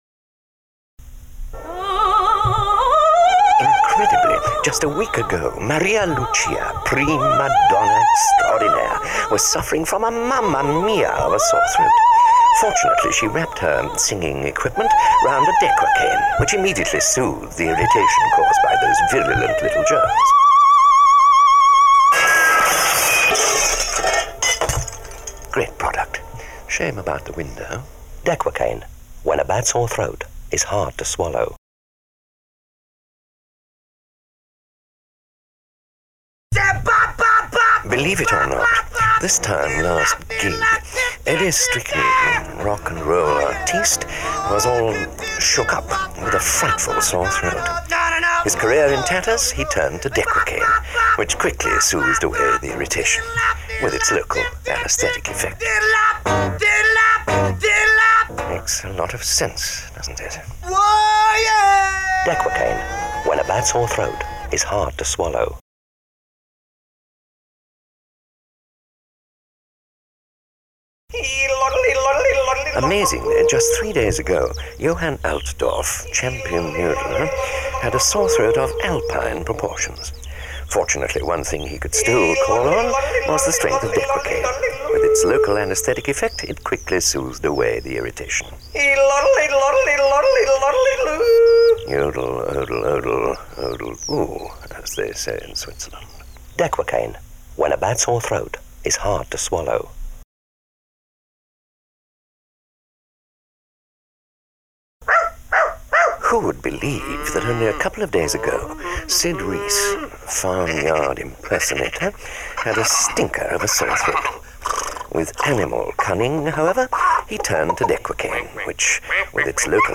Dequacaine Radio Ad